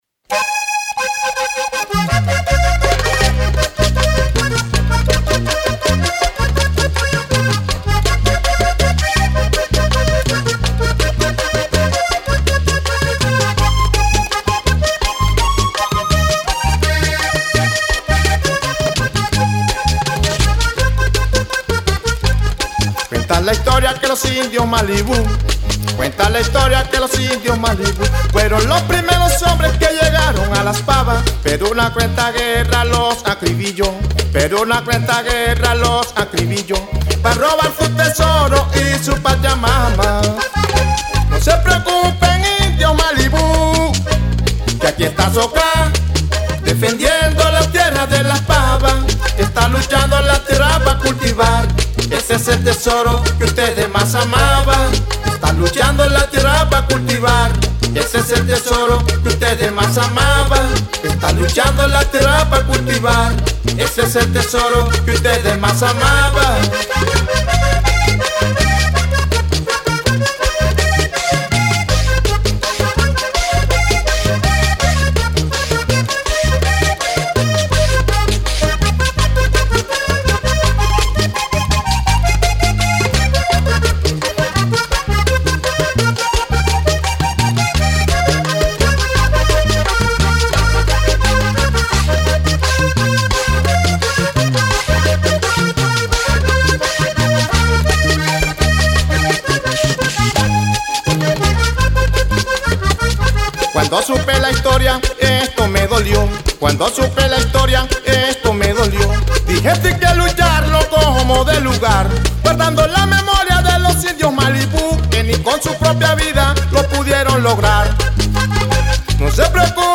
Canción
voz.
caja.
guacharaca.
acordeón.